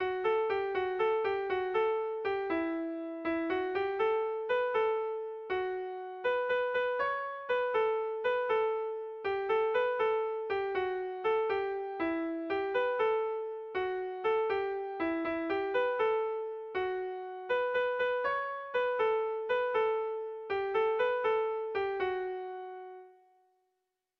Gabonetakoa
AB